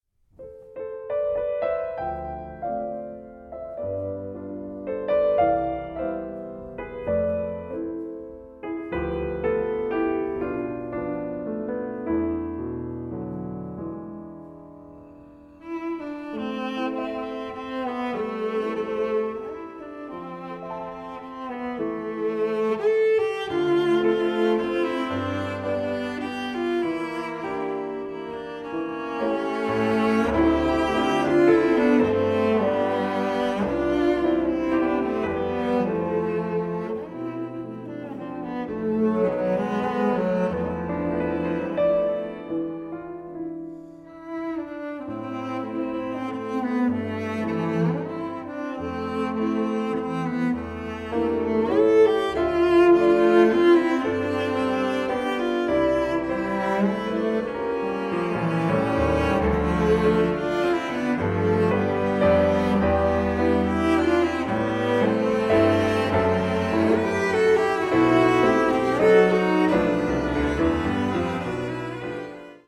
CELLO MUSIC